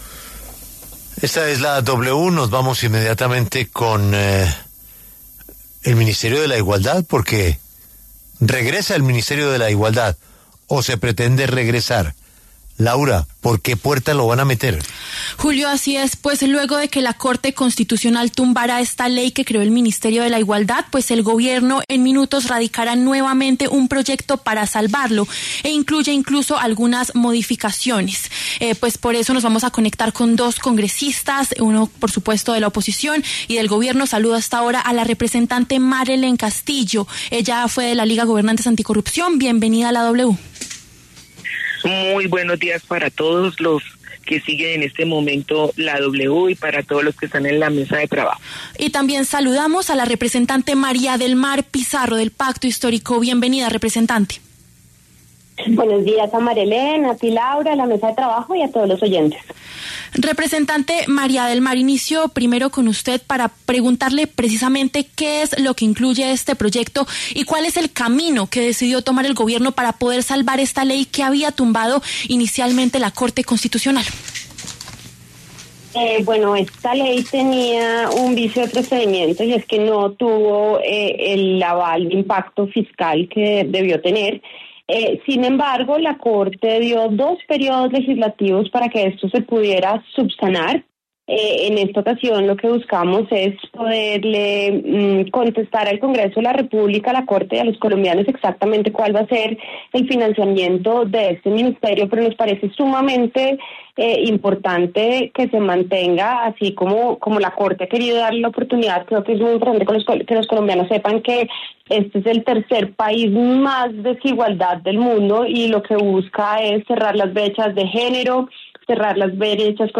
Las representantes Marelen Castillo, de oposición, y María del Mar Pizarro, del Pacto Histórico, pasaron por los micrófonos de La W.